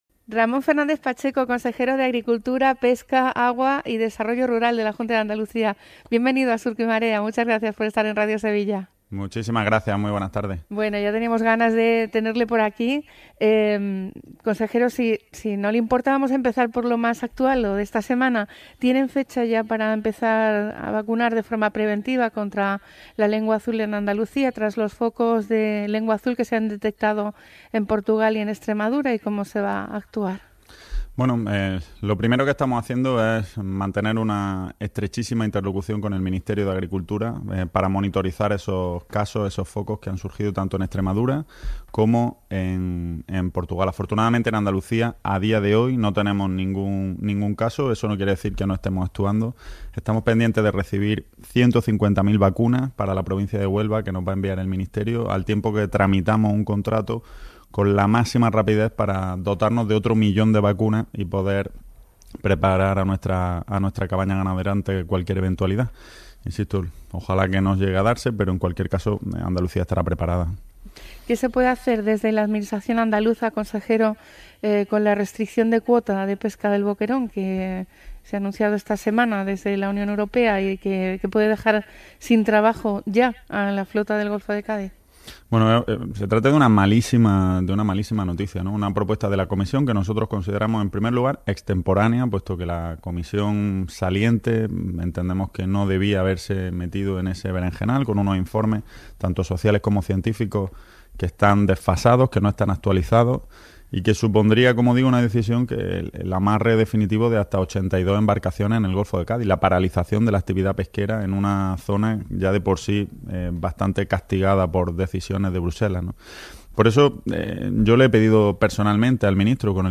En una entrevista en el programa Surco y Marea de la Cadena Ser Andalucía, Fernández ha destacado la amplia experiencia de los Grupos de Desarrollo Rural en los territorios y su capacidad para vertebrar y dinamizar los territorios rurales.
entrevista-ramon-fernandez-pacheco-consejero-agricultura-pesca-agua-y-dr-andaluc.mp3